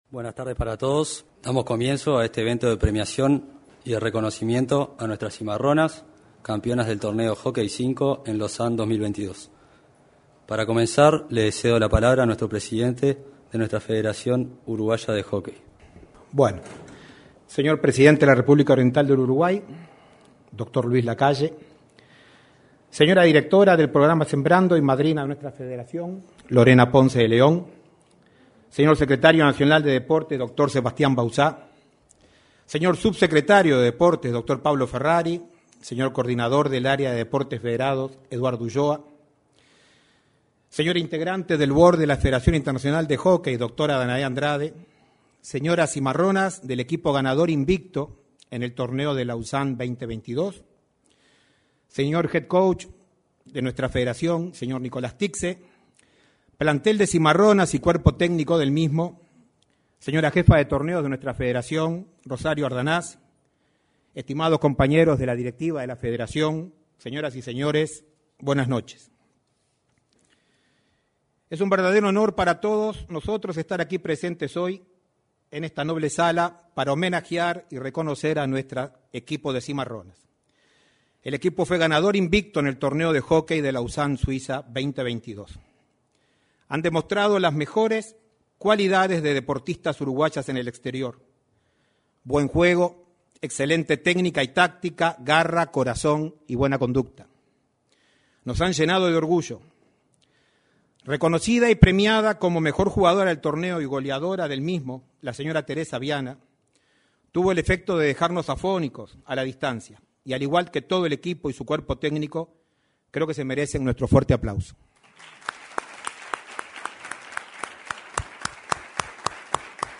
El evento tuvo lugar en el salón de actos de la Torre Ejecutiva.